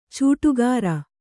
♪ cūṭugāra